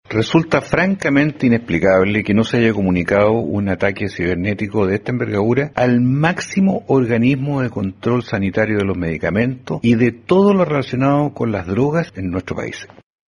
El senador Juan Luis Castro, de la comisión de Salud, cuestionó la falta de transparencia del organismo frente a un hecho de esta gravedad.